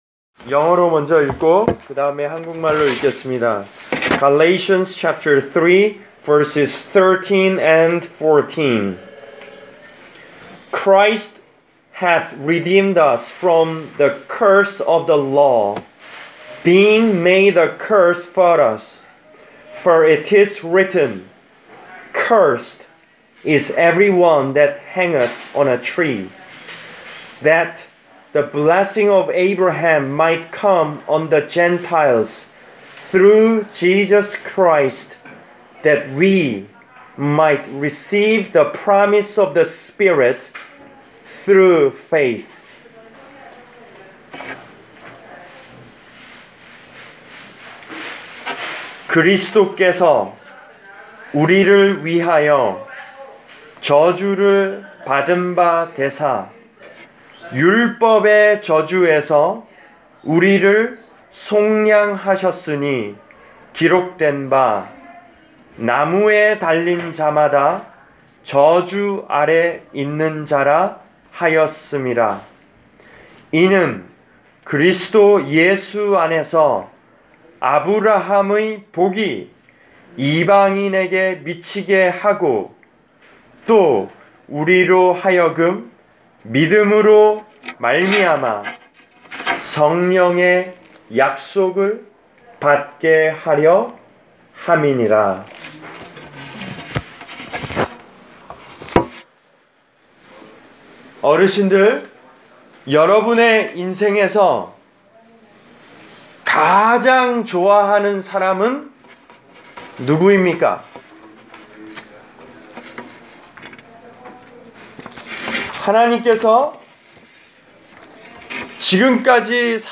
Preached for: Country Arch Care Center, Pittstown, N.J.